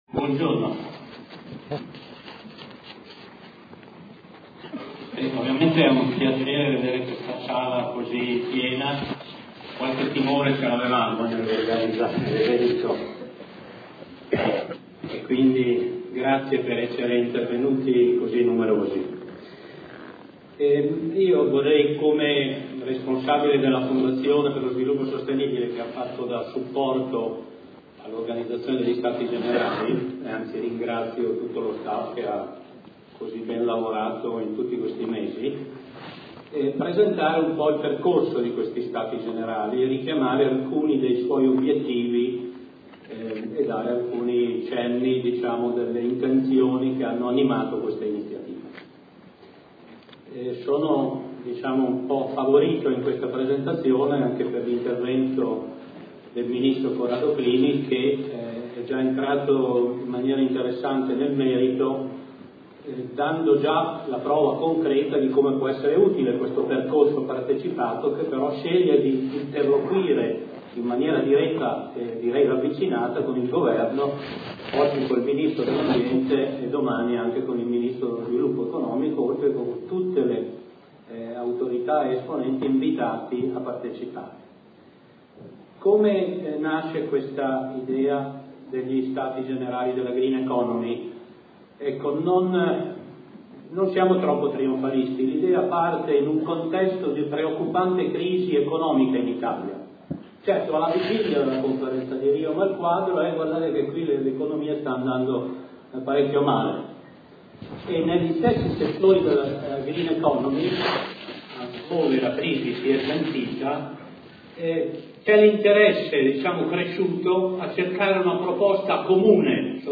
Ecomondo key Energy - Rimini, 7-8 novembre 2012
Intervento di Edo Ronchi, Presidente della Fondazione per lo sviluppo sostenibile ( > ascolta l'intervento completo ) . Il percorso degli Stati generali della green economy.